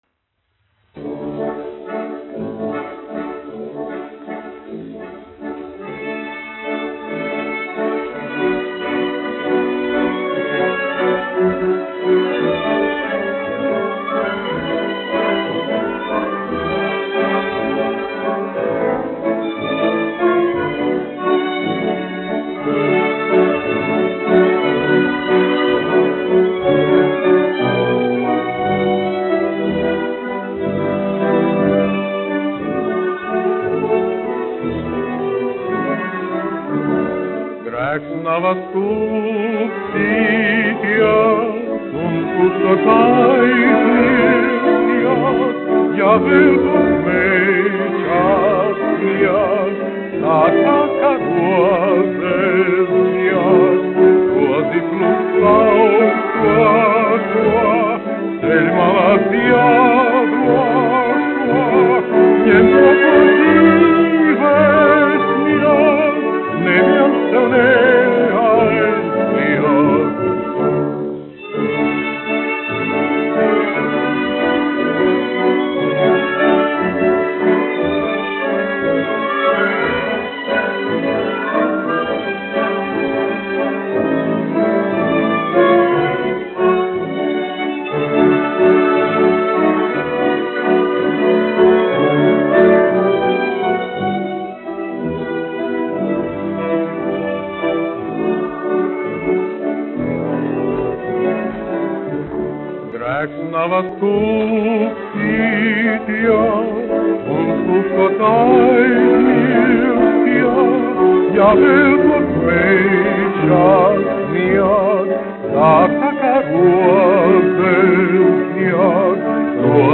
1 skpl. : analogs, 78 apgr/min, mono ; 25 cm
Valši
Populārā mūzika
Operas--Fragmenti, aranžēti
Skaņuplate